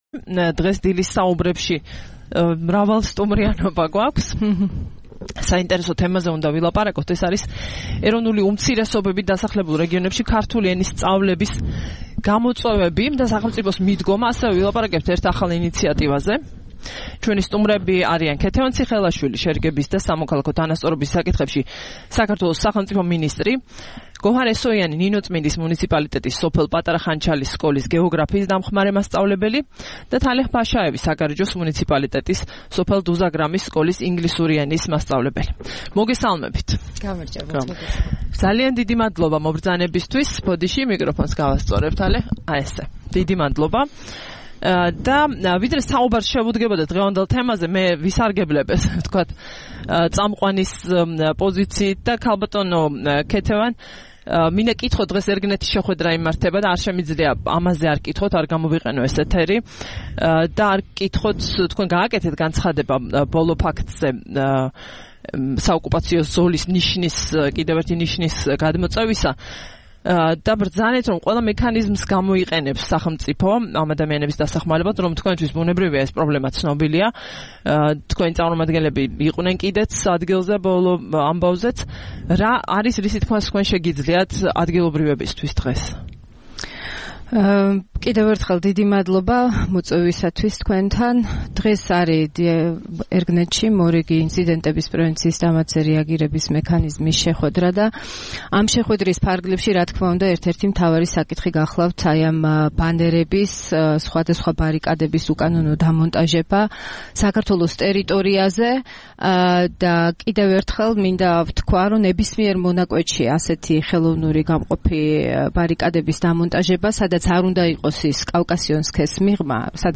11 ივლისს რადიო თავისუფლების "დილის საუბრების" სტუმრები იყვნენ: